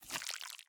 Minecraft Version Minecraft Version snapshot Latest Release | Latest Snapshot snapshot / assets / minecraft / sounds / item / honeycomb / wax_on1.ogg Compare With Compare With Latest Release | Latest Snapshot